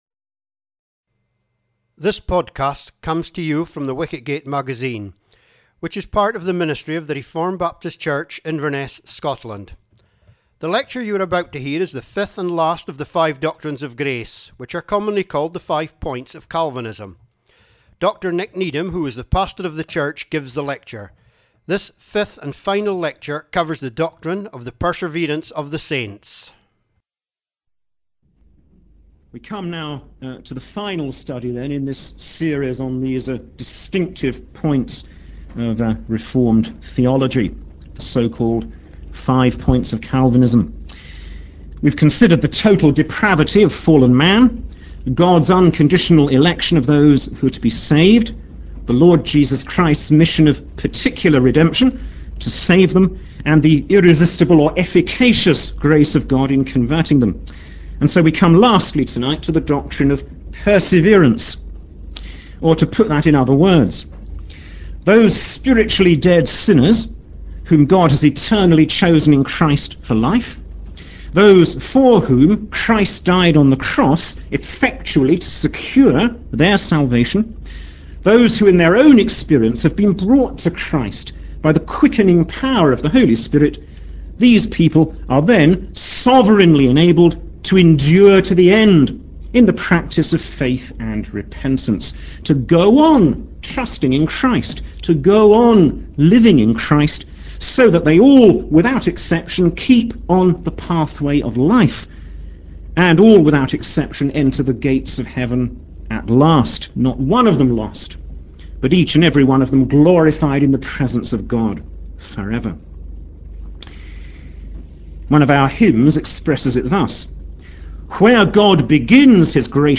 The doctrines are also called the doctrines of Grace. These lectures are practical and interesting.